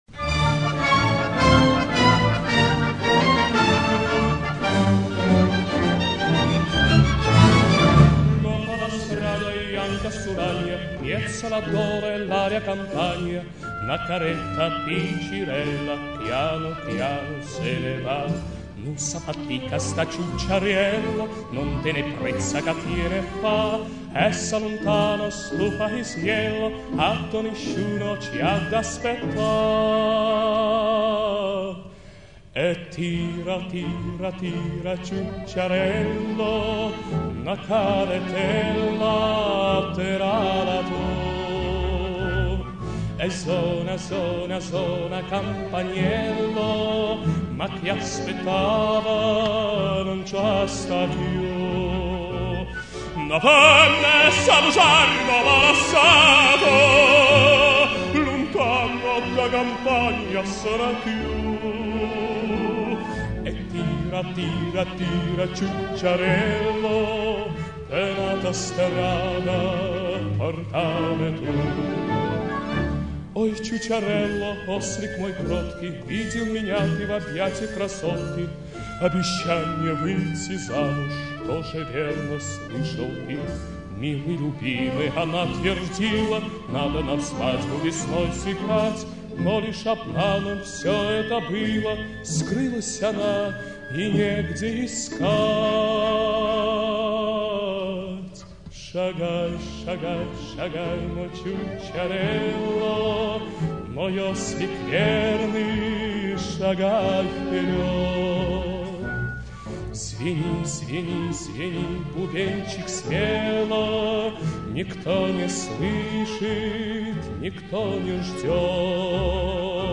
Italian songs.